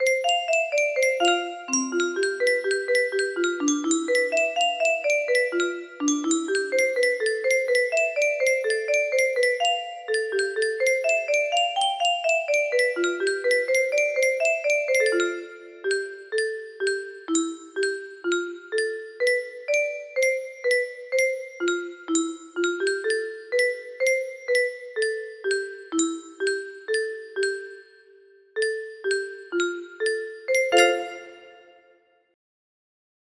Looking back yesteryear music box melody
Ti.sig.: 4/4
Temp.: 125
Key: C maj
*Mode: F lydian